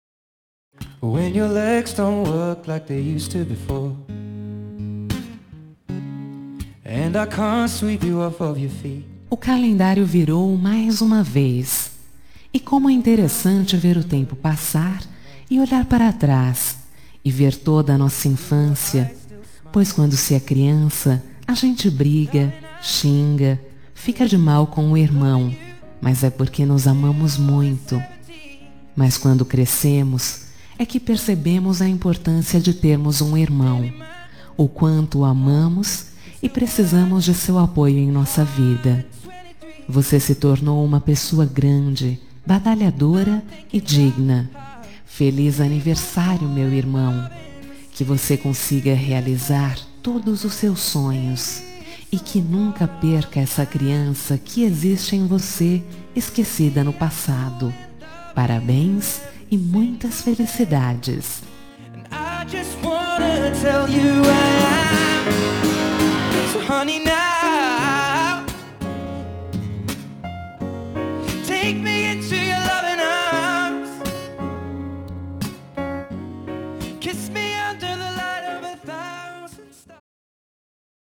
Telemensagem de Aniversário de Irmão – Voz Feminina – Cód: 1697